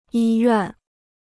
医院 (yī yuàn): hospital.